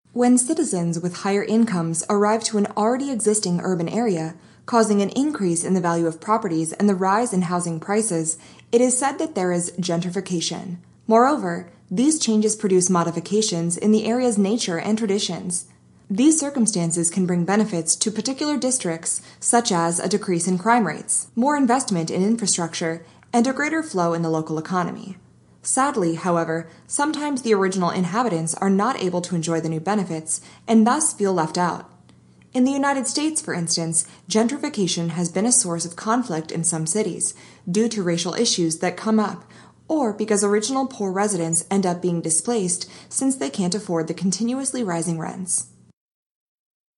Q. The main idea of the lecture is to  —–